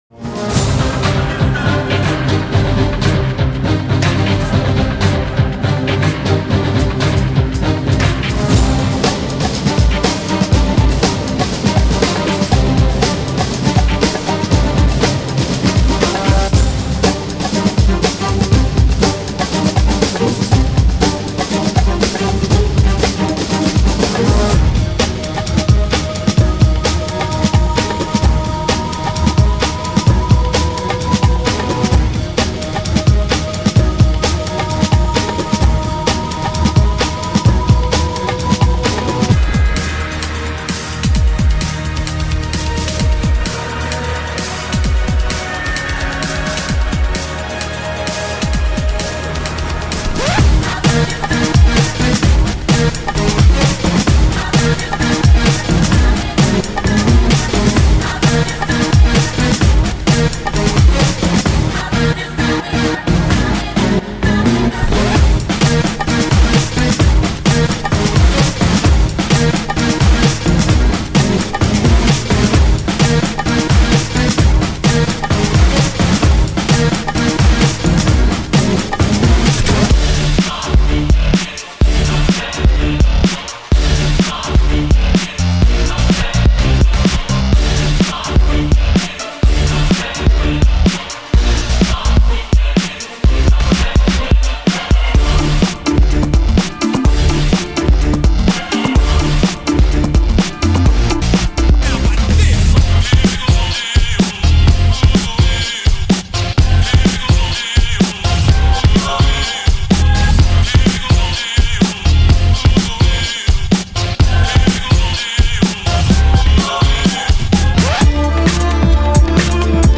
Electrofunk, Bass & Technobreaks VINYLs